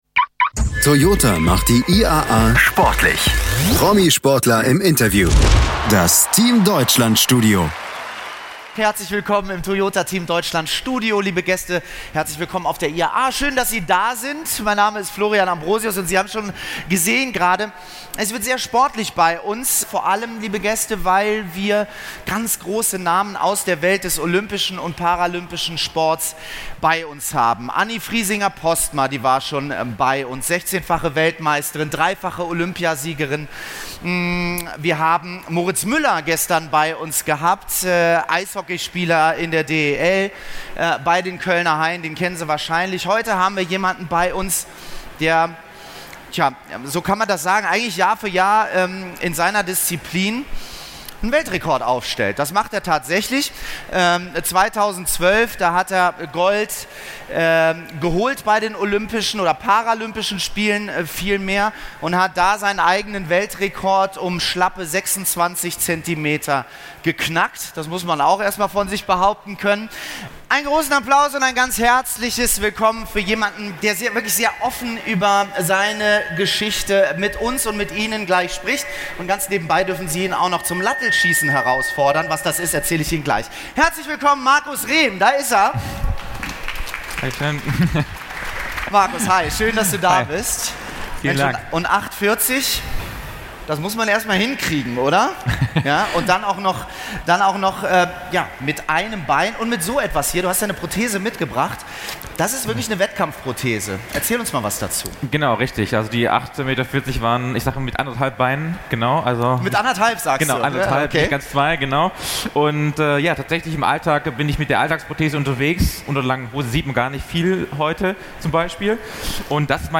Markus Rehm im Interview ~ Behindertensport Podcast
Auf der diesjährigen Internationalen Automobil Ausstellung IAA begrüßt Toyota deshalb täglich Promi-Sportler an seinem Stand.
In dieser Ausgabe ist Markus Rehm im Team Deutschland Studio zu Gast. Er ist unter anderem zweifacher Olympiasieger im Weitsprung.